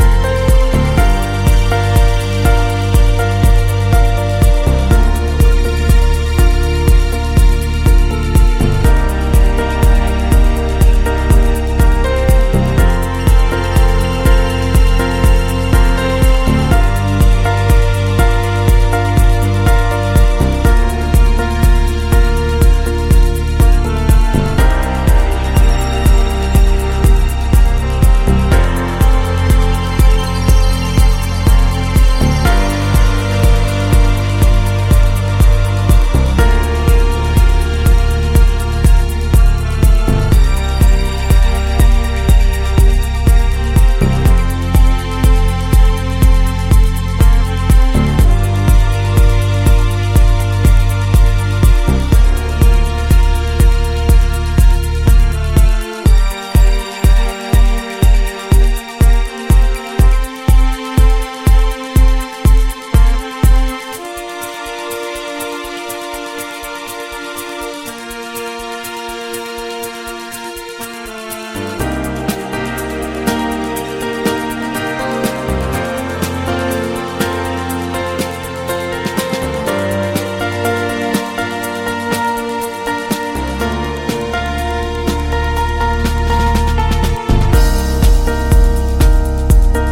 Styl: Progressive, House, Techno